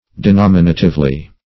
Denominatively \De*nom`i*na"tive*ly\